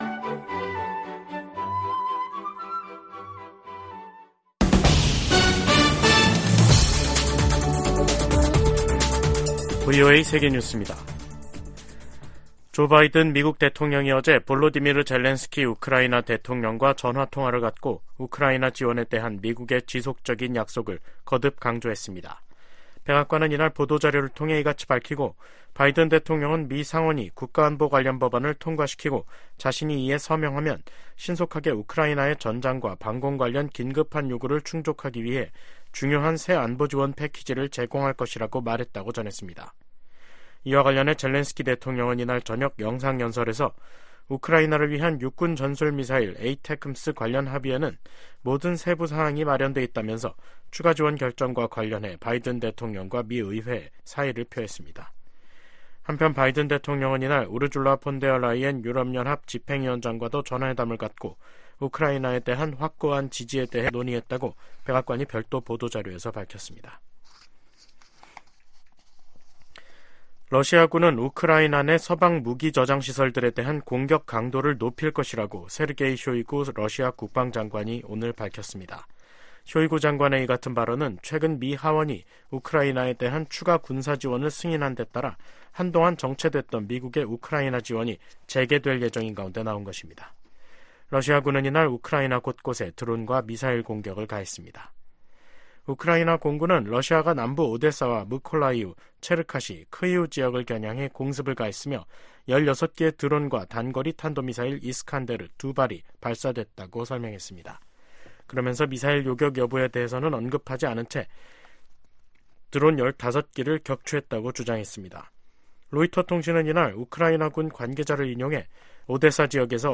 VOA 한국어 간판 뉴스 프로그램 '뉴스 투데이', 2024년 4월 23일 2부 방송입니다. 미국 국무부는 2023 인권보고서에서 북한이 당국 차원에서 살인과 고문 등 광범위한 인권 유린 행위를 여전히 자행하고 있다고 지적했습니다. 유엔은 북한의 22일 단거리 탄도미사일 발사에 대해 이는 명백한 국제법 위반이라고 지적했습니다.